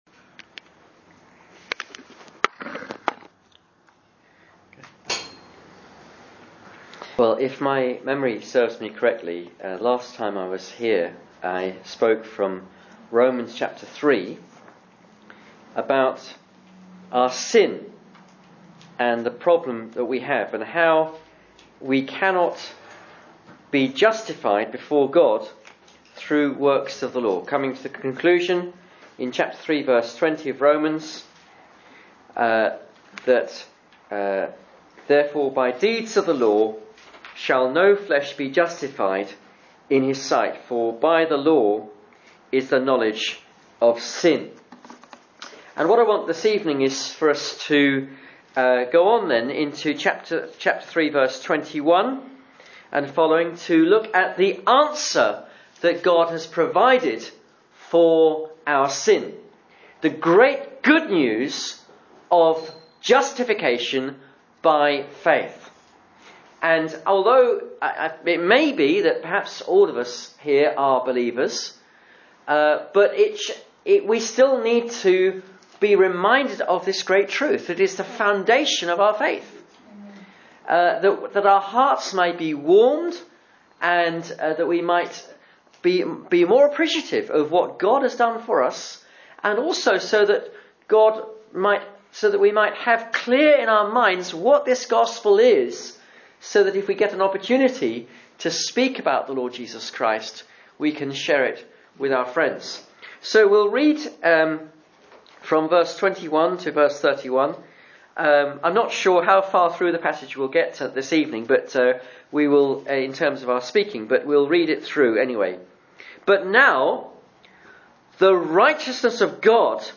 Romans 3:21-31 Service Type: Sunday Evening Service « Luke 9:57-62